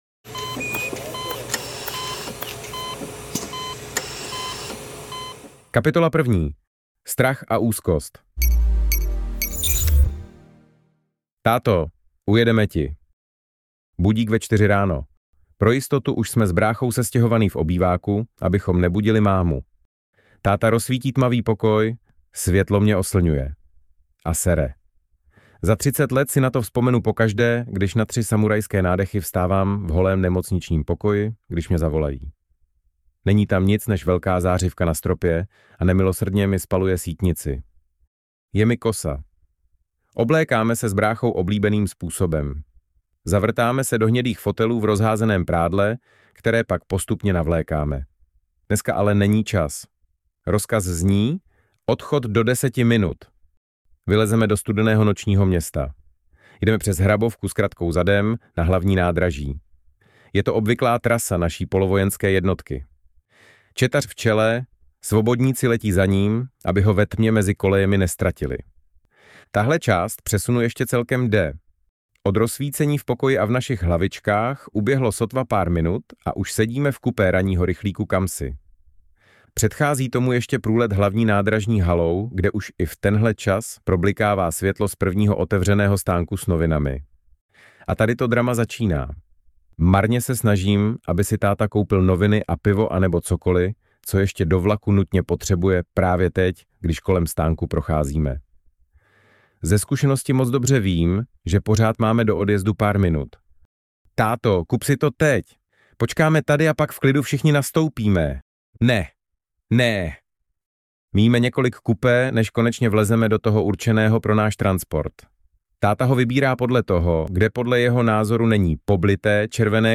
Objektivní nález audiokniha
Ukázka z knihy
• InterpretTomáš Šebek, AI hlasový klon Tomáše Šebka